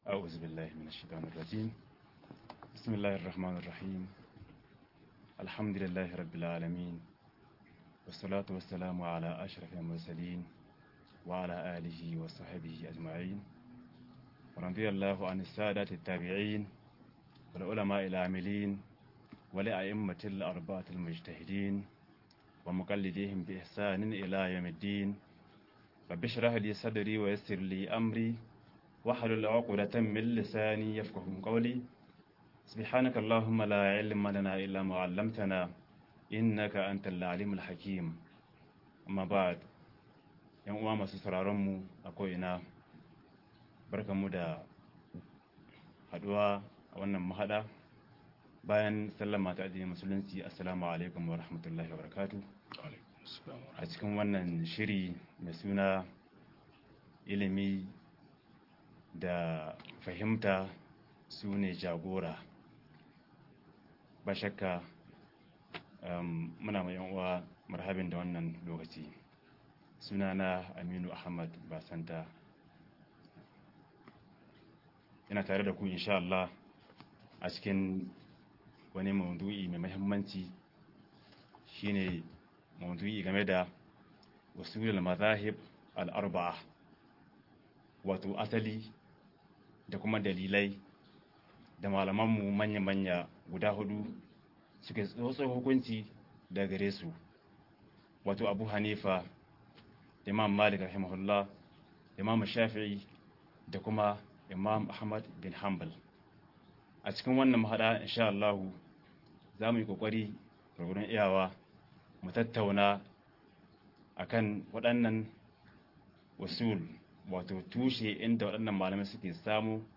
Asalin mazhabobin fiqhu-01 - MUHADARA